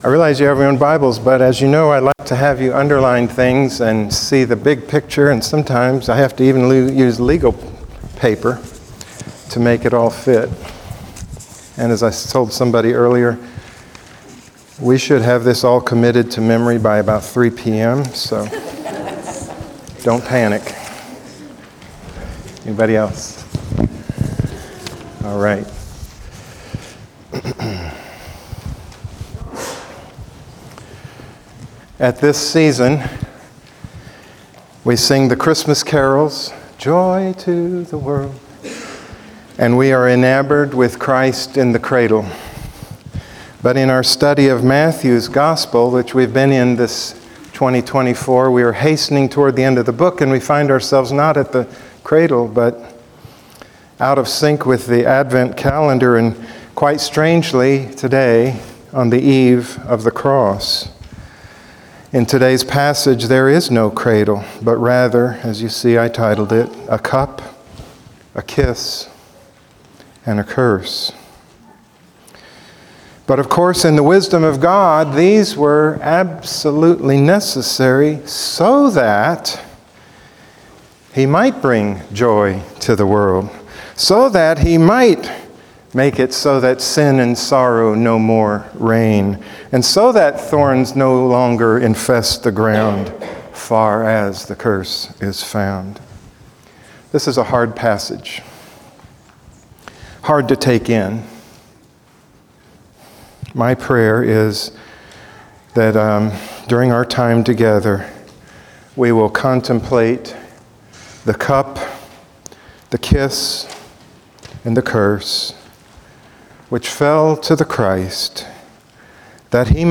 A Cup, a Kiss, and a Curse - Woodland Hills Community Church